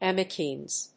イーマシーンズ